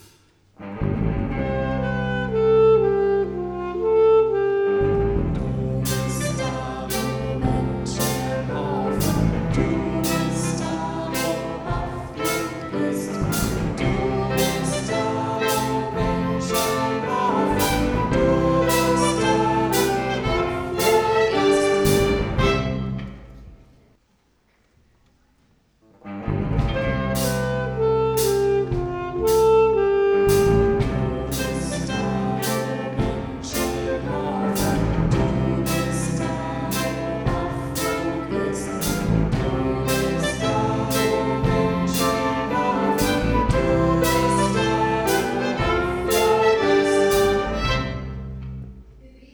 Schülerinnen und Schüler aller Klassenstufen gestalten den musikalischen Part der Gottesdienste und Schulfeiern. Gespielt werden moderne, meist geistliche Lieder und Instrumentaleinlagen.
Diese reicht querbeet von Querflöten, Klarinetten bis Schlagzeug über (E-)Gitarren, Violinen, verschiedene Blasinstrumente, Klavier und Gesang.